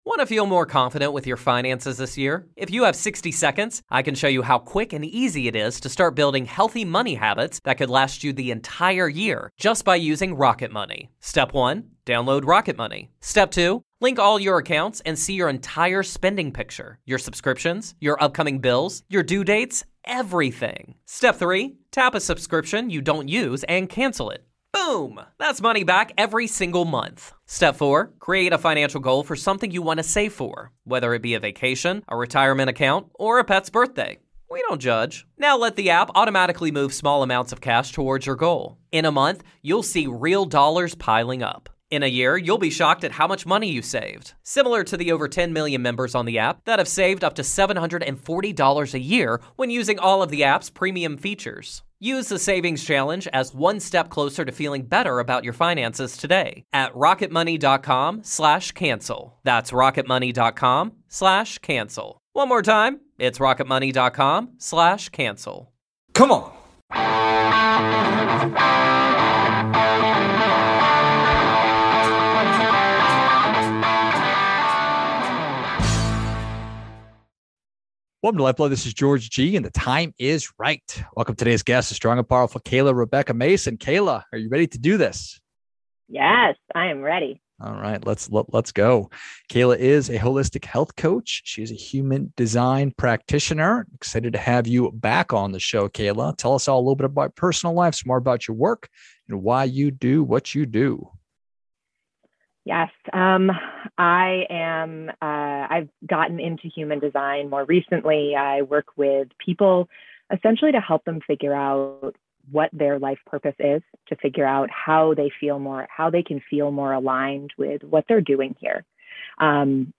LifeBlood: We conducted a live Human Design reading